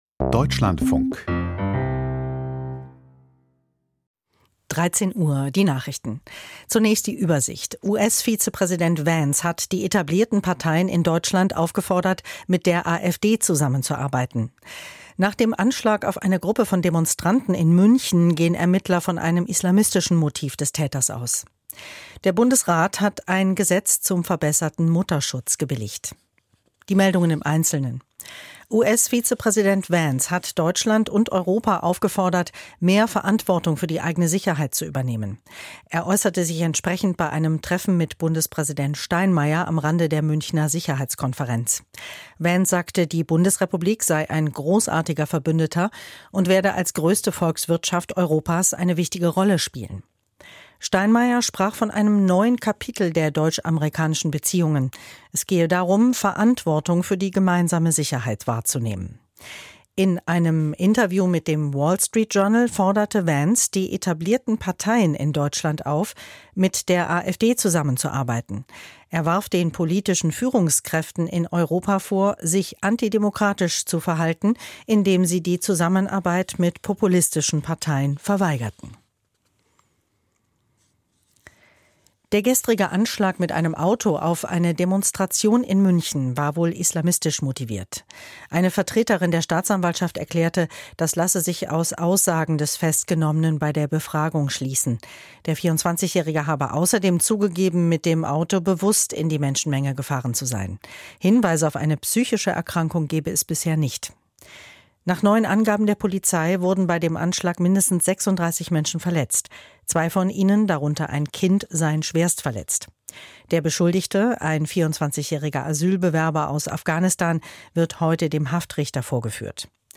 Die Deutschlandfunk-Nachrichten vom 11.03.2025, 10:30 Uhr.